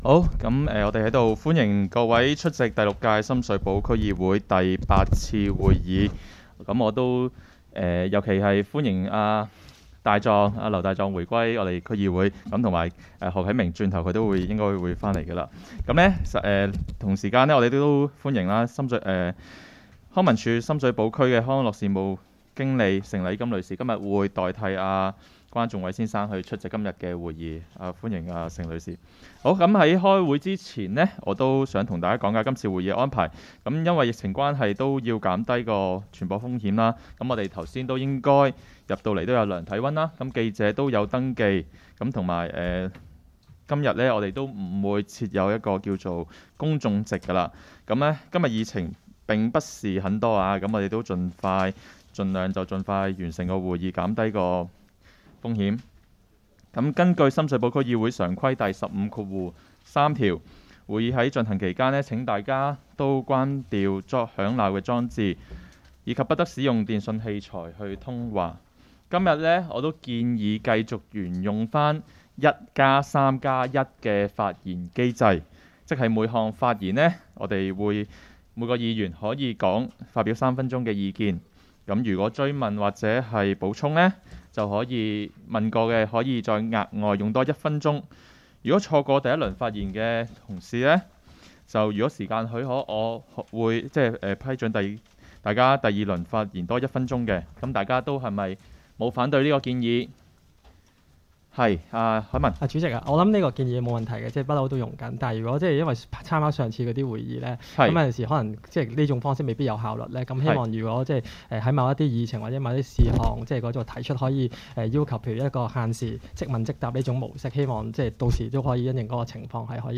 区议会大会的录音记录
深水埗区议会会议室